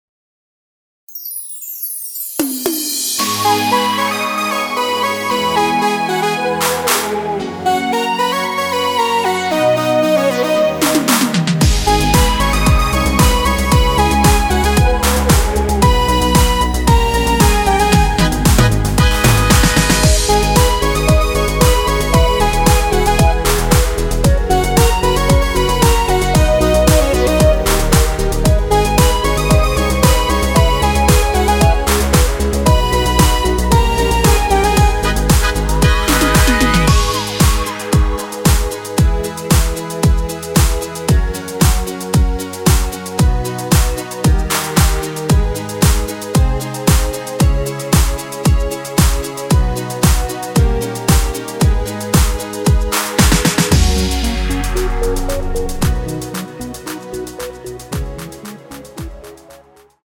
원키에서(-6)내린 남성분이 부르실수 있는 키의 MR입니다.
G#m
앞부분30초, 뒷부분30초씩 편집해서 올려 드리고 있습니다.
중간에 음이 끈어지고 다시 나오는 이유는